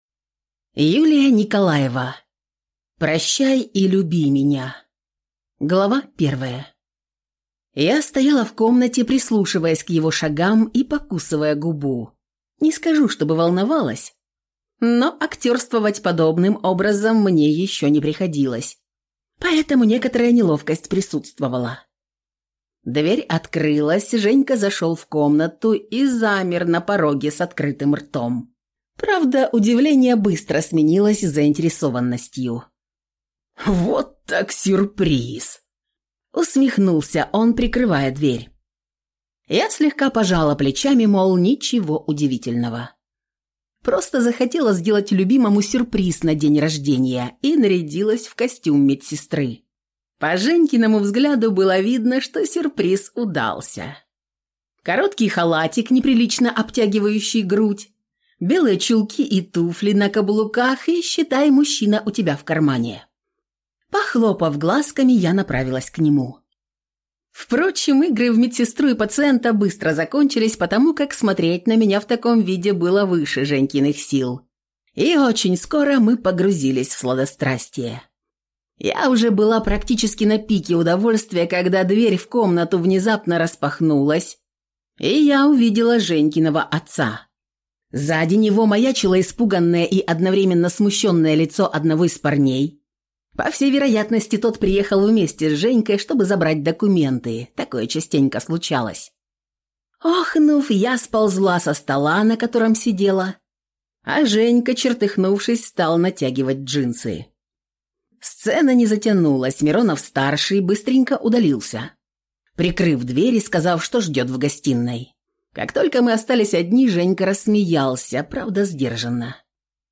Аудиокнига Прощай и люби меня | Библиотека аудиокниг